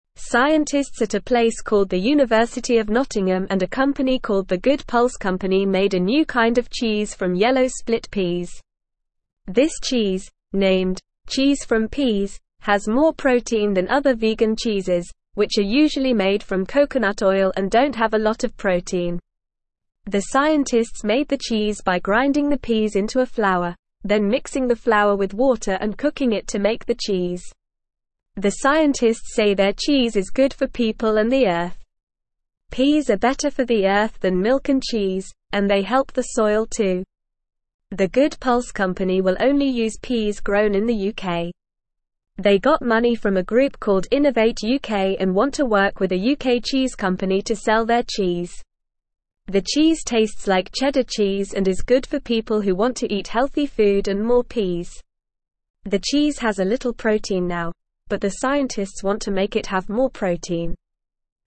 Normal
English-Newsroom-Beginner-NORMAL-Reading-New-Cheese-Made-from-Peas-Tasty-and-Healthy.mp3